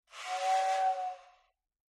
Toy Electric Train; Whistle With Train Buzz On Rev.